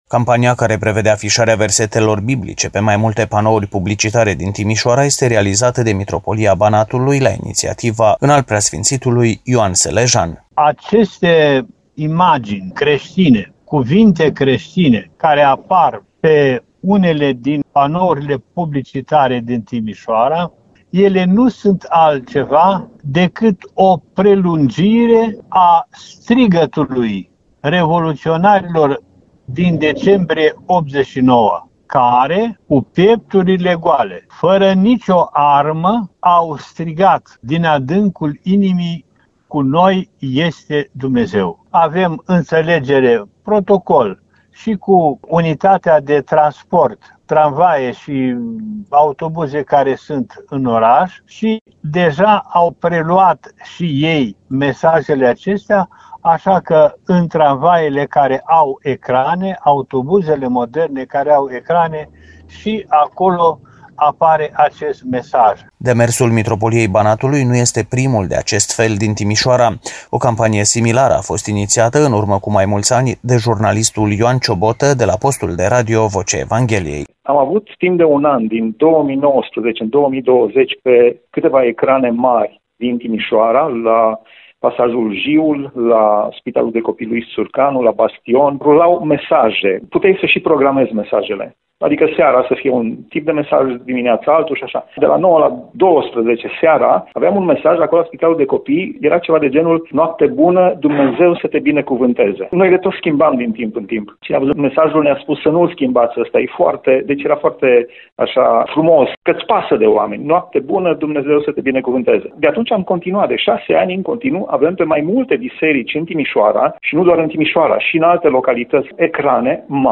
Ce spun trecătorii